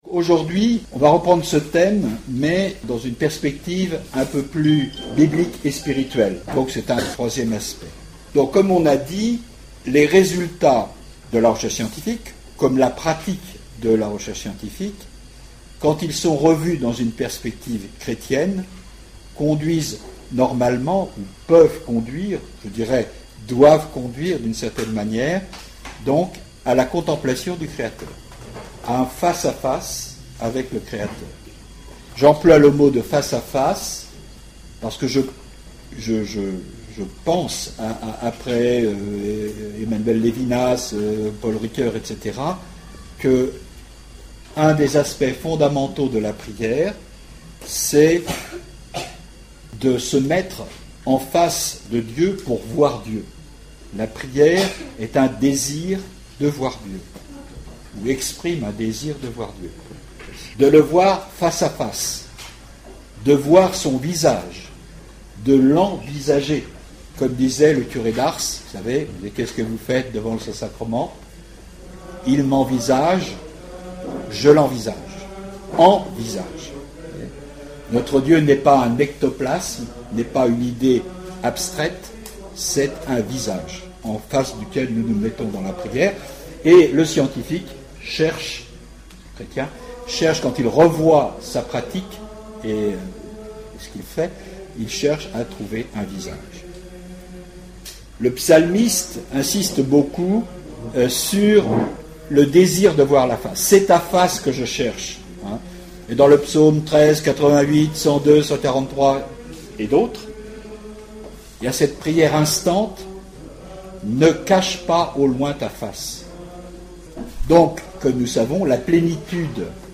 Enseignement
Session famille 1 (du 28 juillet au 2 août 2012)
La qualité technique du premier enregistrement est assez médiocre, mais son contenu mérite d'être diffusé.
Format :MP3 64Kbps Mono